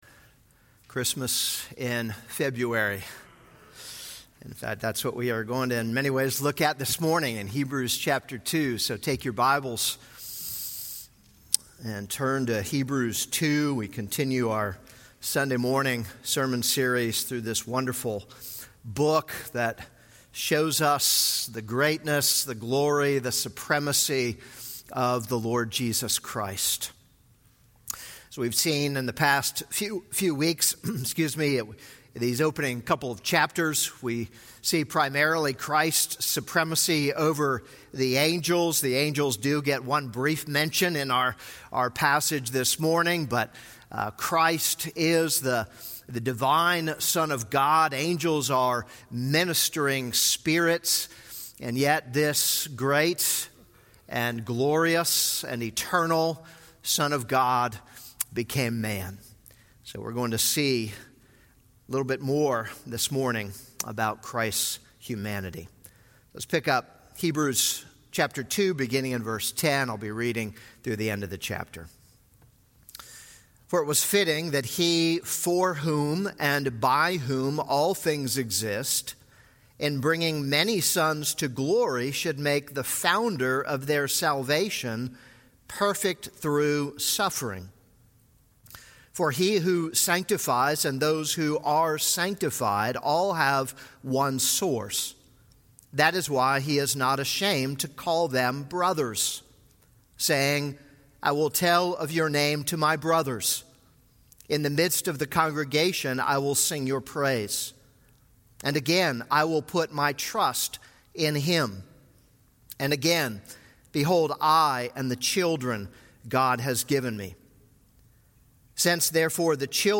This is a sermon on Hebrews 2:10-18.